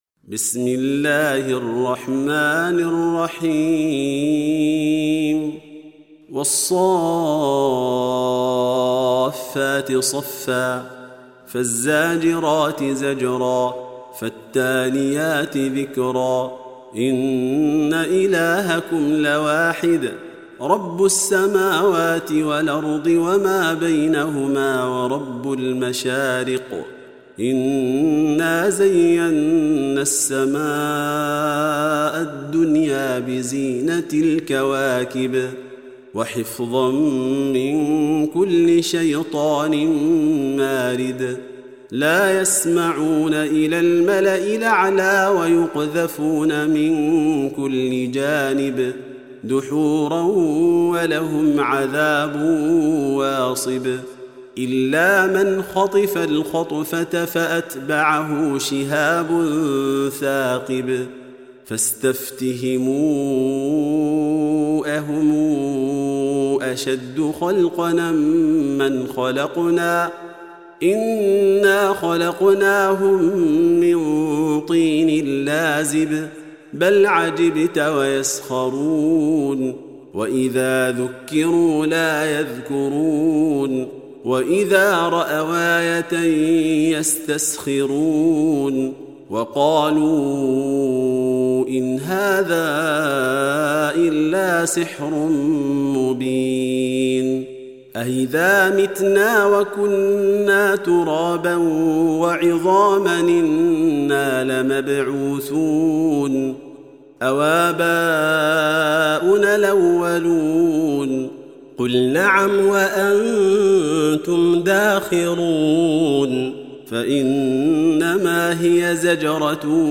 Surah Repeating تكرار السورة Download Surah حمّل السورة Reciting Murattalah Audio for 37. Surah As-S�ff�t سورة الصافات N.B *Surah Includes Al-Basmalah Reciters Sequents تتابع التلاوات Reciters Repeats تكرار التلاوات